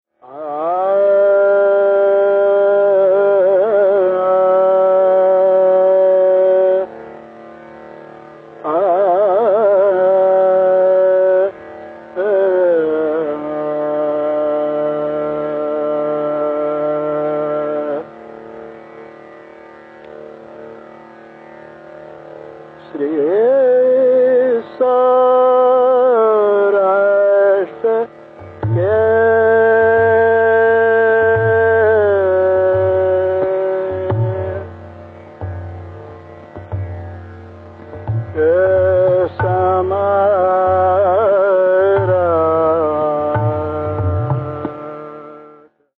S-r-G-m-P-dD-N-S
A unique ‘double-Dha’ blend of the morning Bhairav with the night-time Bhinna Shadja, Saurashtra Bhairav’s movements vary across gharana boundaries.
Core form: SrGmPdDNS
• Tanpura: Sa–Pa